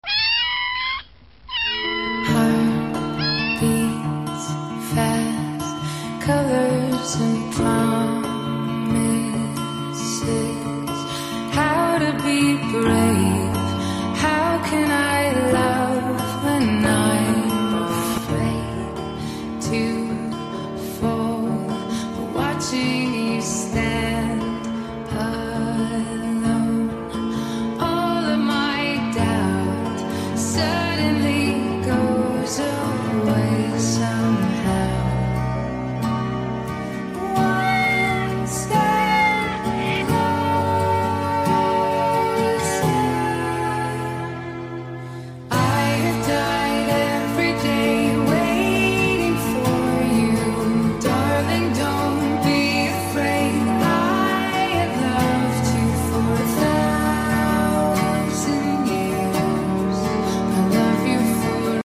A kitten called out to sound effects free download
A kitten called out to me on the road